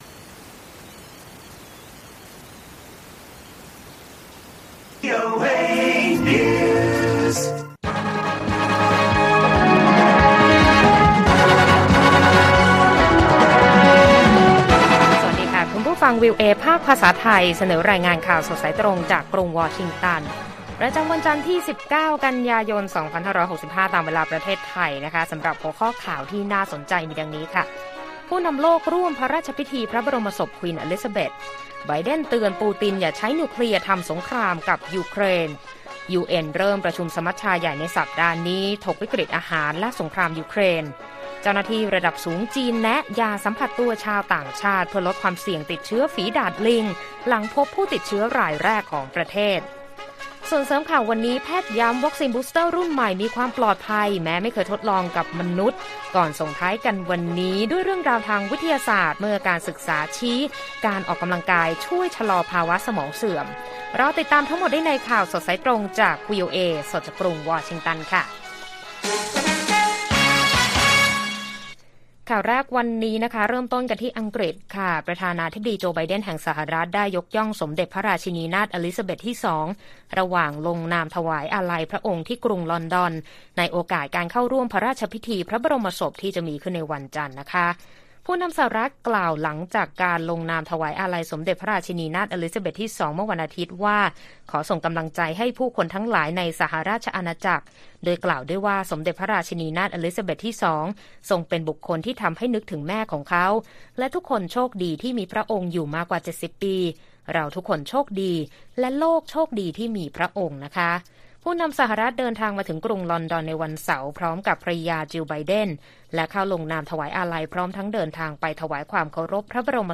ข่าวสดสายตรงจากวีโอเอไทย วันจันทร์ ที่ 19 ก.ย. 2565